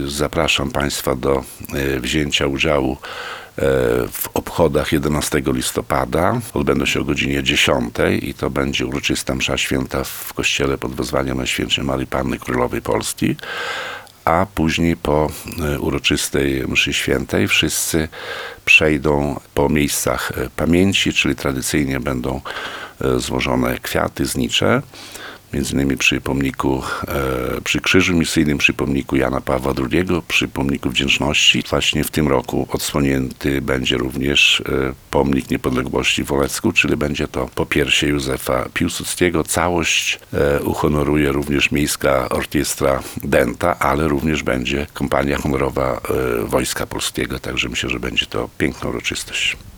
Zaprasza Wacław Olszewski, burmistrz Olecka.